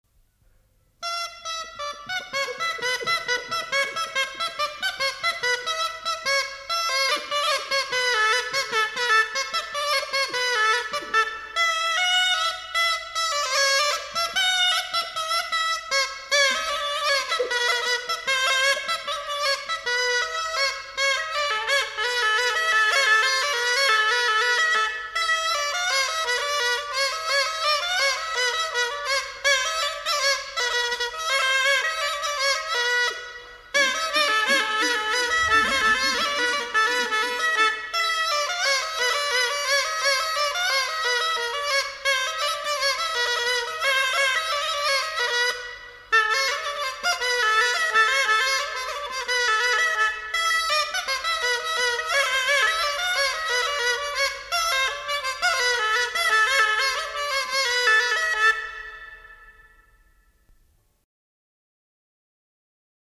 Голоса уходящего века (Курское село Илёк) Камаринская (рожок, инструментальный наигрыш)